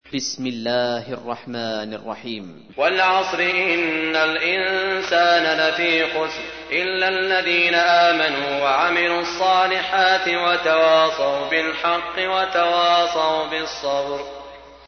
تحميل : 103. سورة العصر / القارئ سعود الشريم / القرآن الكريم / موقع يا حسين